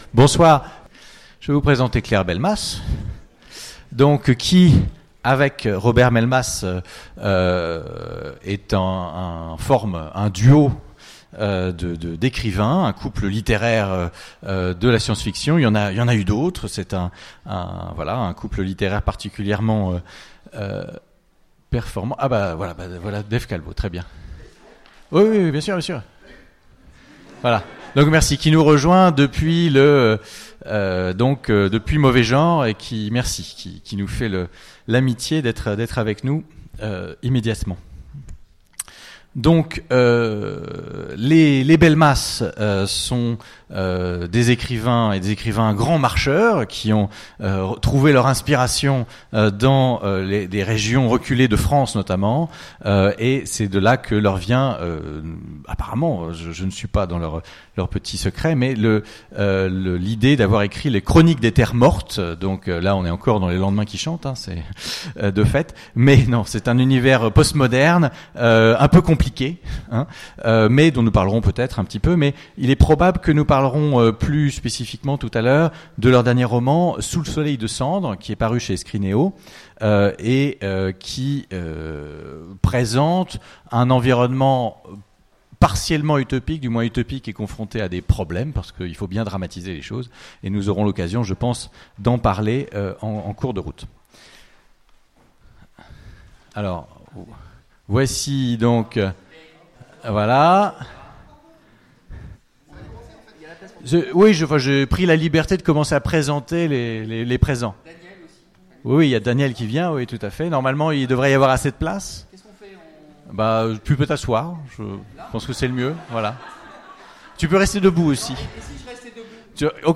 Utopiales 2017 : Conférence Les lendemains qui chantent de la science-fiction